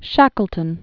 (shăkəl-tən), Sir Ernest Henry 1874-1922.